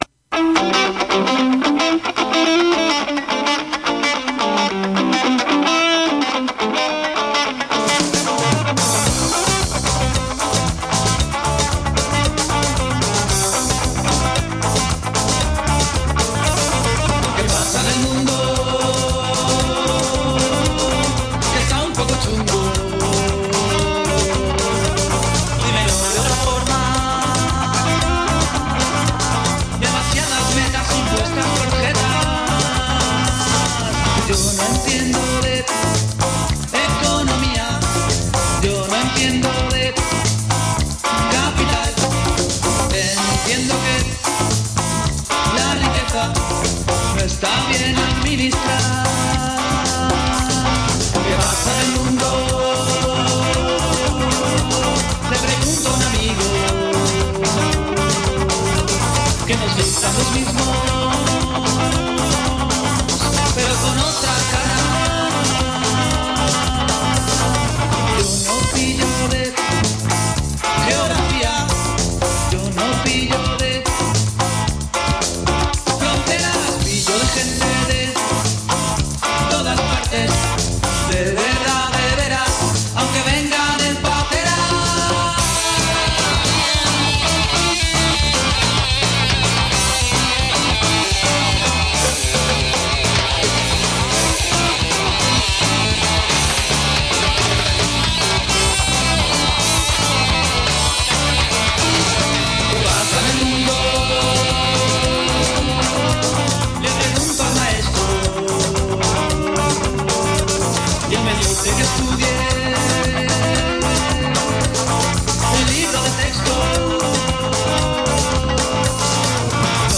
Tertulia referente a la violencia institucional, estatal, económica y política, ejercida por los estados capitalistas hacia las distintas poblaciones y acerca de la función y el papel que desempeña la educación en el actual modelo capitalista.